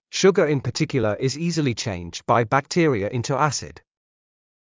ｼｭｶﾞｰ ｲﾝ ﾊﾟﾃｨｷｭﾗｰ ｲｽﾞ ｲｰｼﾞﾘｰ ﾁｪﾝｼﾞﾄﾞ ﾊﾞｲ ﾊﾞｸﾃﾘｱ ｲﾝﾄｳ ｱｼｯﾄﾞ